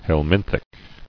[hel·min·thic]